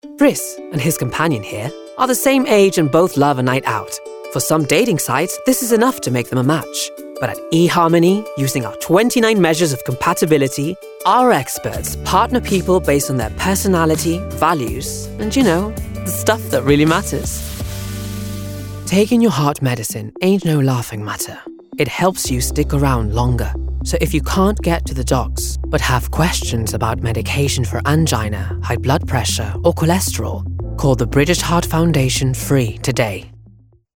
Sprechprobe: Werbung (Muttersprache):
I have a home studio setup as well: Nova Black Condenser Mic, AIR 192|4 Interface, Computer Specs: 10.15.5 MAC OS X, Recording Software: Logic Pro X